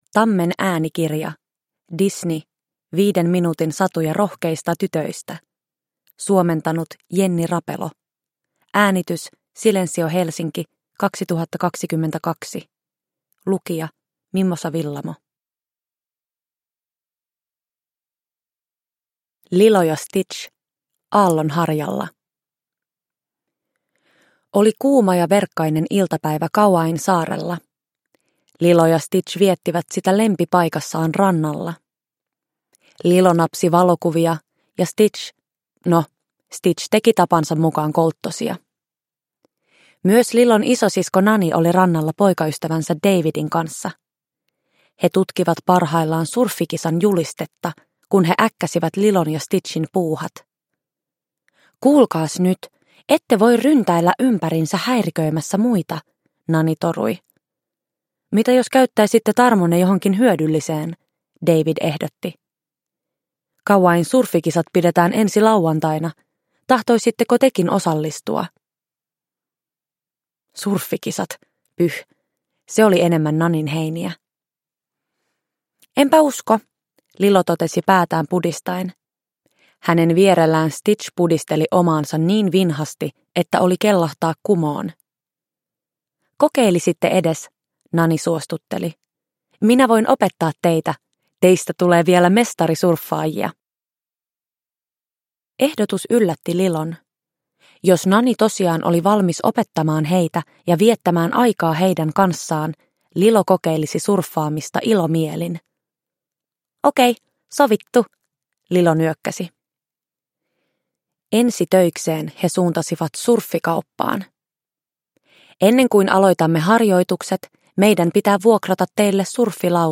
Disney. 5 minuutin satuja rohkeista tytöistä – Ljudbok – Laddas ner